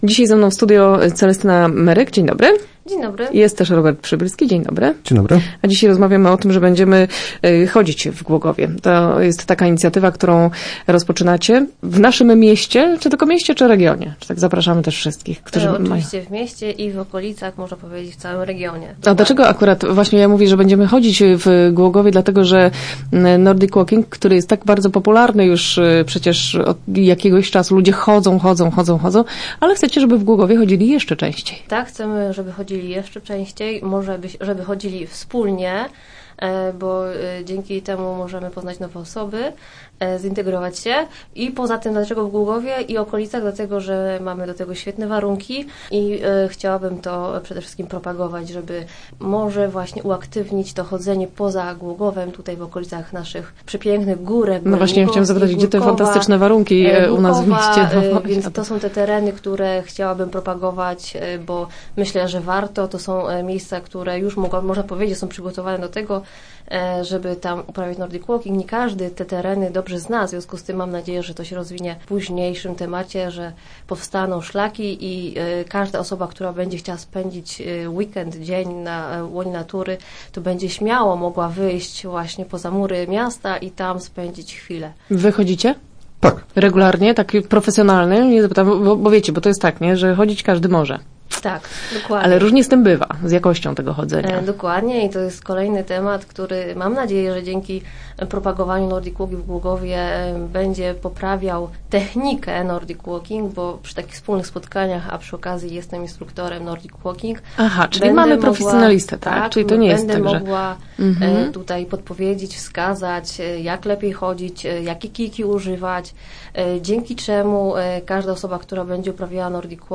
Start arrow Rozmowy Elki arrow Rozchodzić Głogów